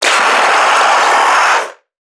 NPC_Creatures_Vocalisations_Infected [16].wav